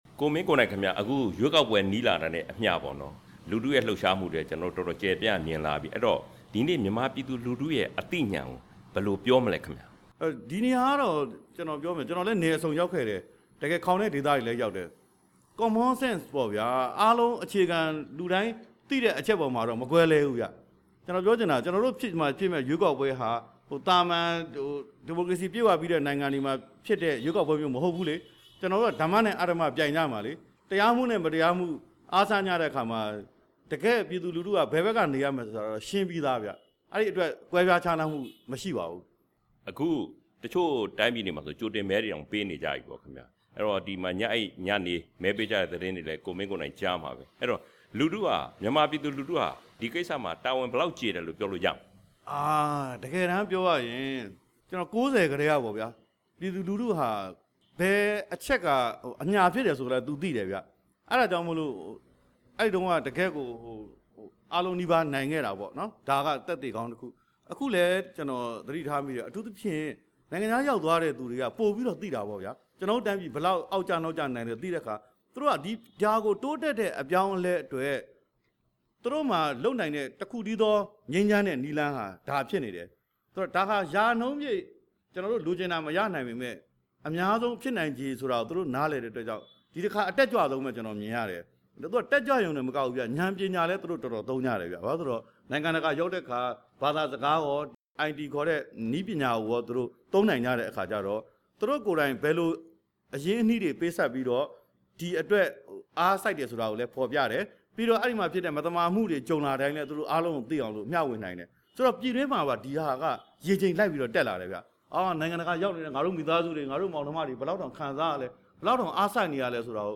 ၈၈ မျိုးဆက် ငြိမ်းပွင့်ခေါင်းဆောင် ကိုမင်းကိုနိုင်နဲ့ မေးမြန်းချက်